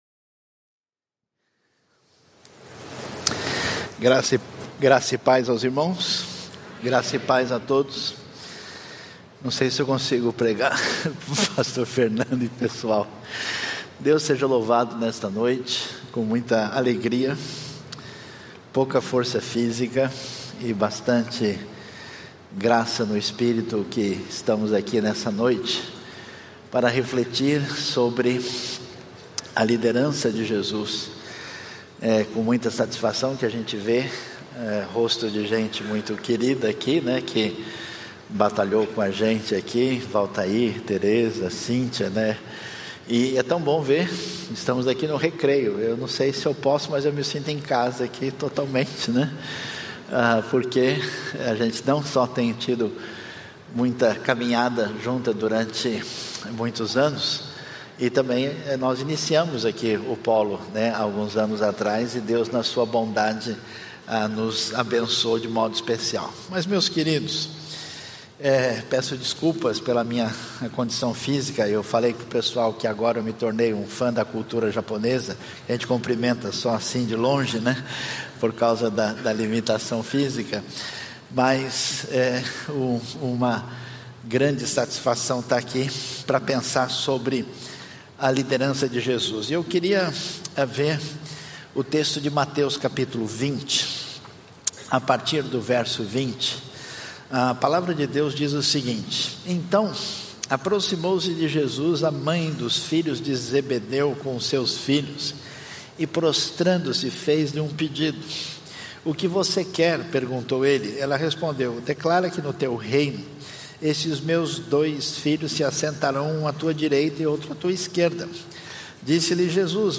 Conferência Teológica #4